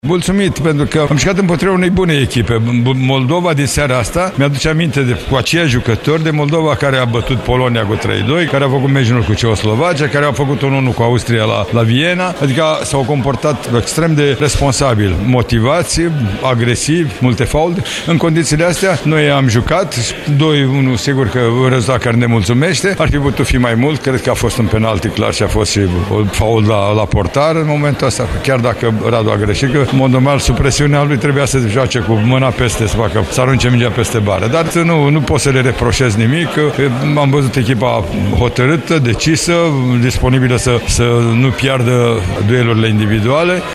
La final, selecționerul Mircea Lucescu s-a declarat per total mulțumit de prestația echipei și a lăudat naționala Moldovei: